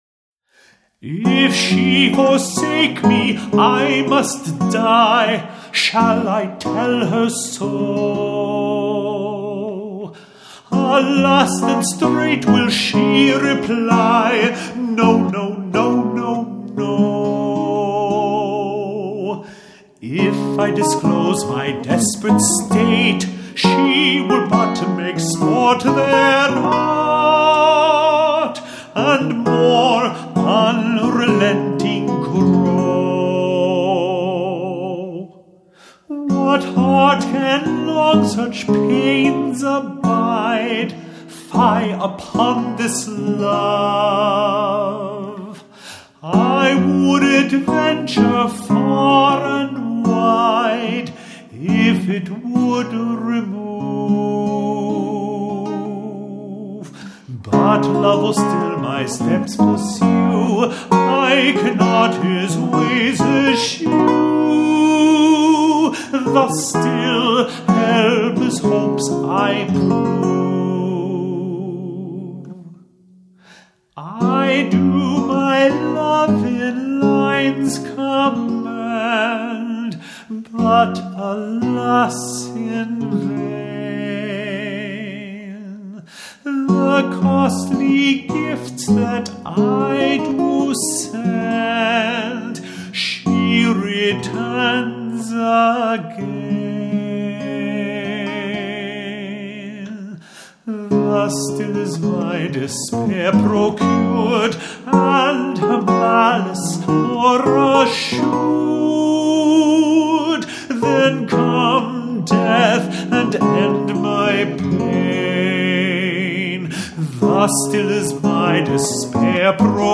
lute songs